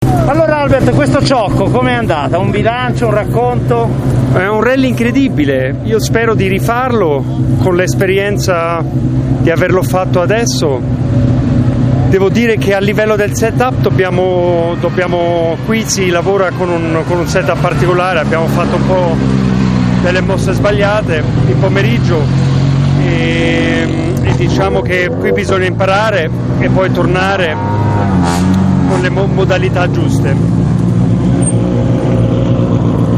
Interviste di fine rally